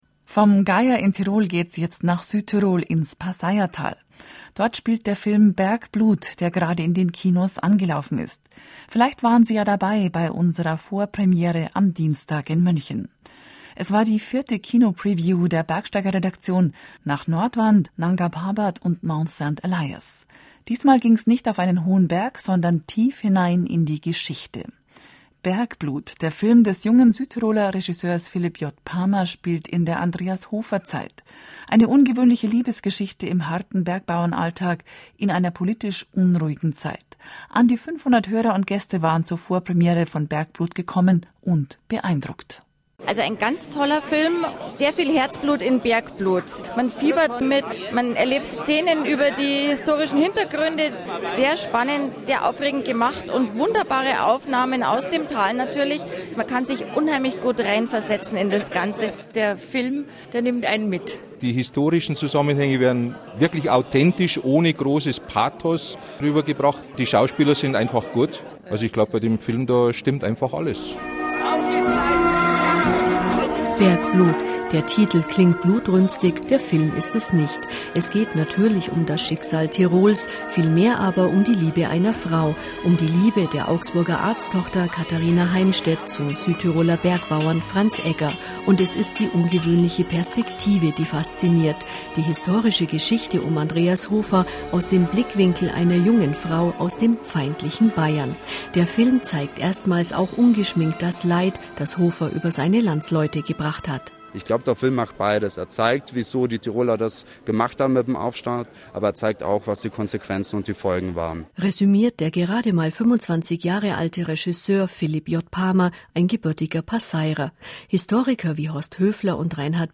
Ein Bericht über die Bergsteiger-Preview im Gloria Palast mit 500 Zuschauern!